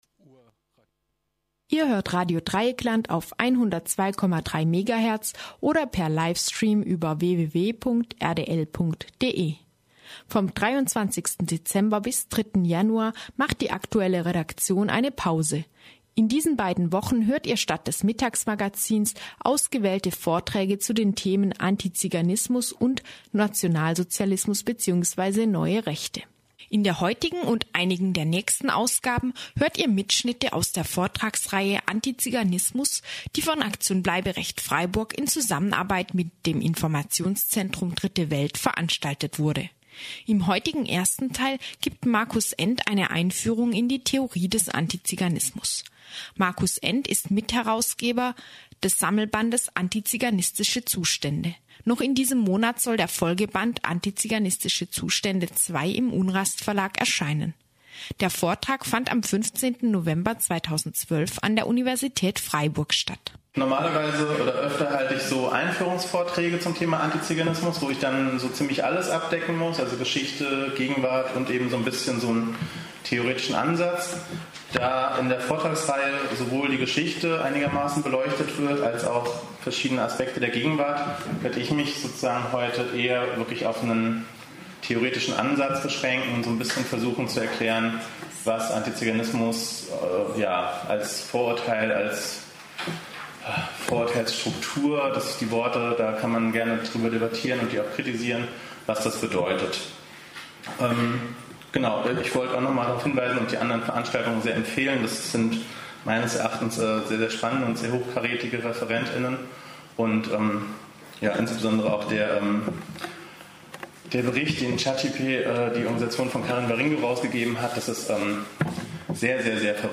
(Ausnahme: Freitag 27.12.2013) Wir bringen dafür Vortragsmitschnitte zu den Themen Antiziganismus und Nationalsozialismus & Neue Rechte